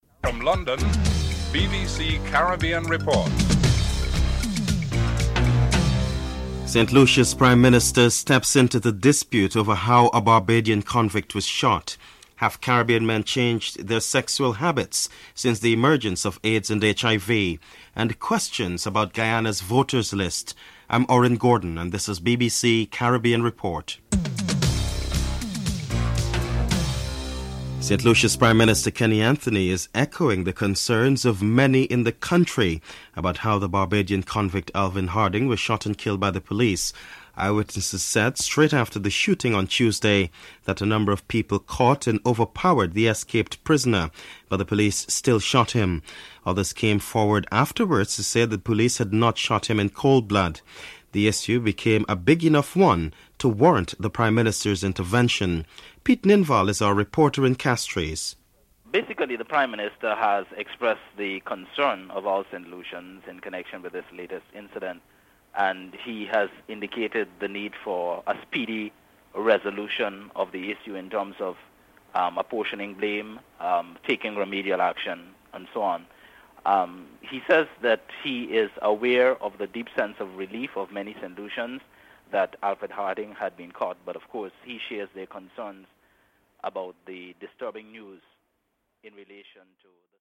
1. Headlines: (00:00-00:26)